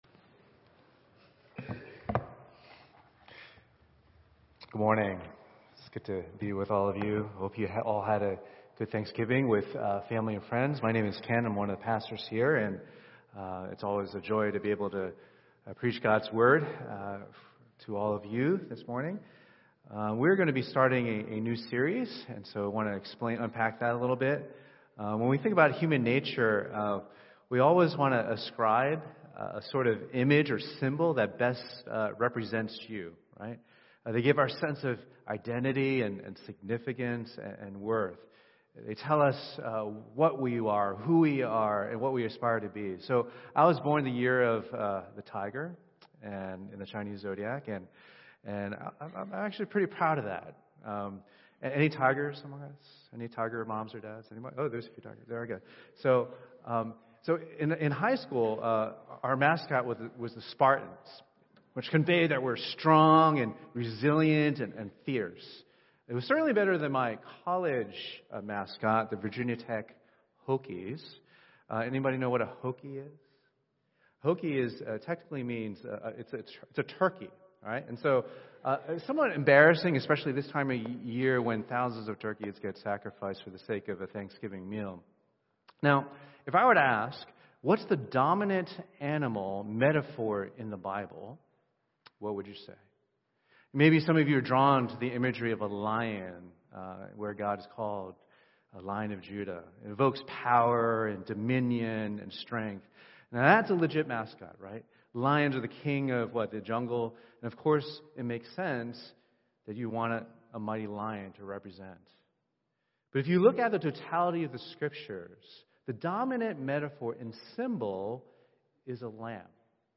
Sermons - Page 8 of 74 | Boston Chinese Evangelical Church